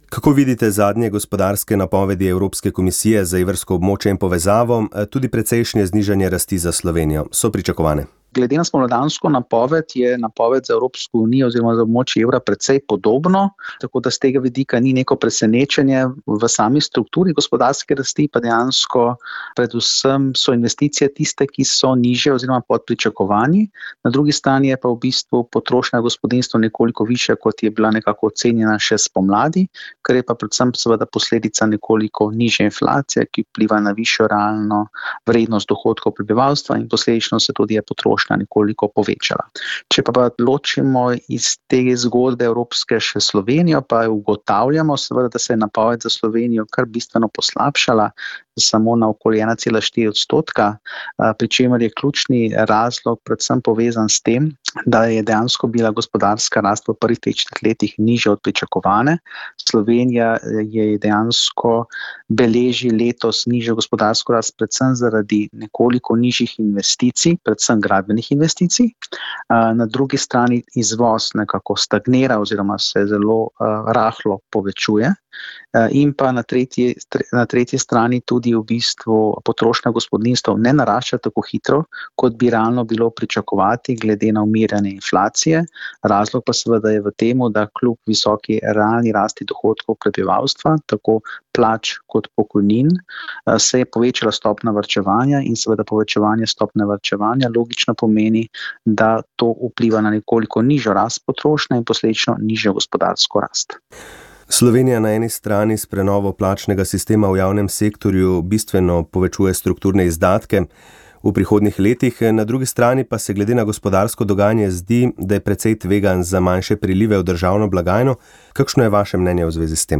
Slovenski poslanec Branko Grims je v pogovoru za Radio Ognjišče podal oster pogled na zaslišanja kandidatov za evropske komisarje ter prihodnost Evropske unije pod vodstvom Ursule von der Leyen. Njegova analiza je temeljila na oceni trenutnih izzivov, predvsem tistih, povezanih z zelenim prehodom, kulturnim marksizmom in vprašanji evropske identitete.